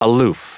Prononciation du mot aloof en anglais (fichier audio)
Prononciation du mot : aloof